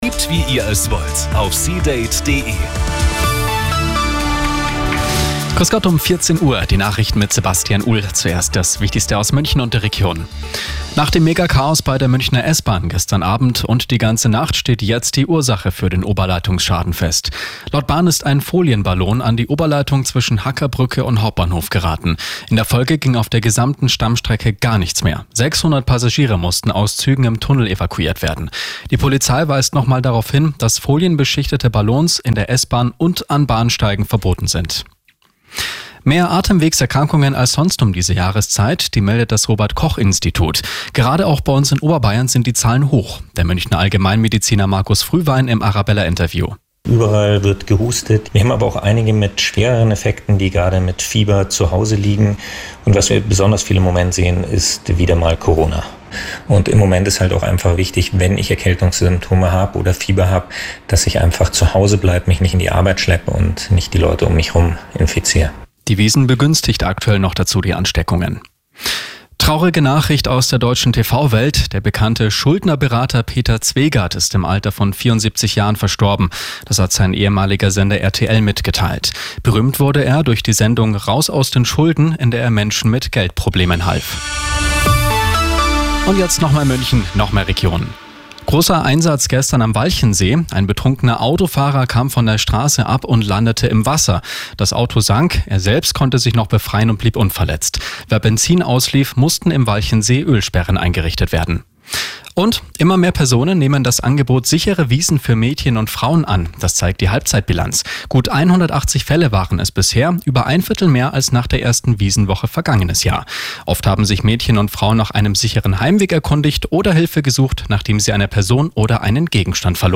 Die Radio Arabella Nachrichten von 16 Uhr - 30.09.2024